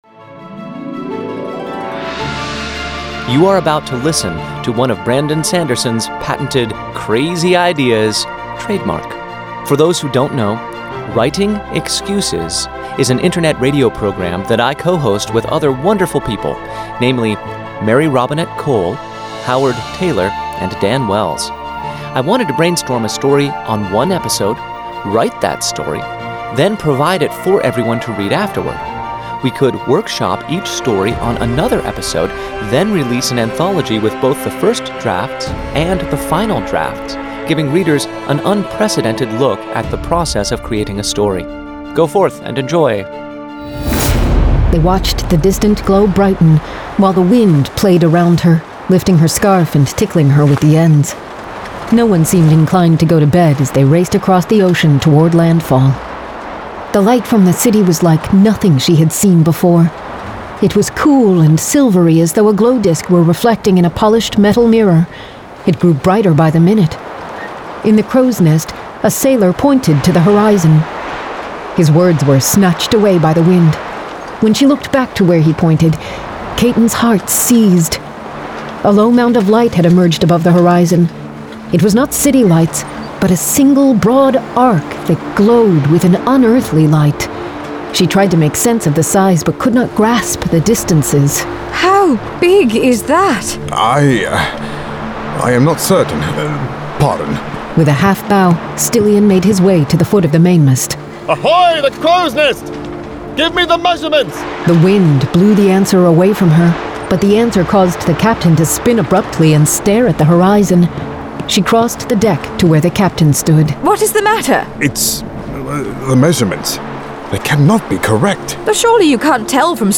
[Dramatized Adaptation]
Genre: Fantasy
Not only does Shadows Beneath have four full cast fantastic works of fiction, but it also includes transcripts of brainstorming and workshopping sessions, early drafts of the stories, essays about the stories’ creation, and details of all the edits made between the first and final drafts.